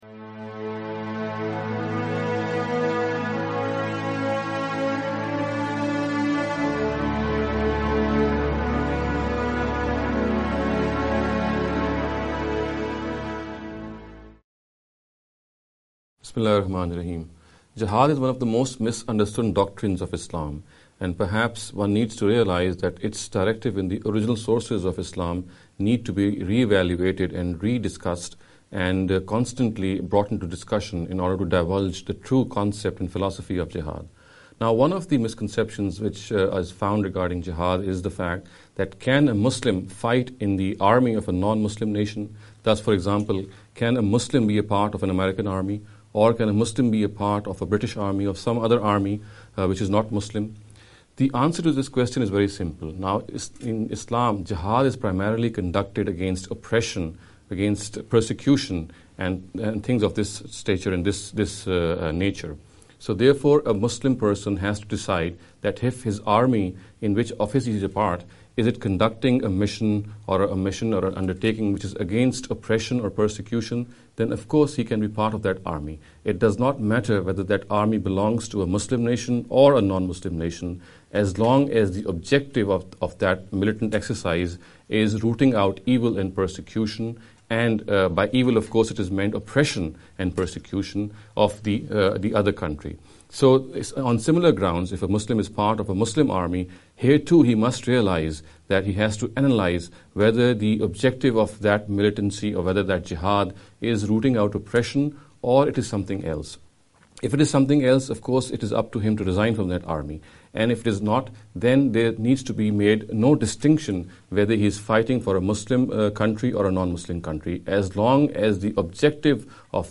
This lecture series will deal with some misconception regarding the Islam and Jihad.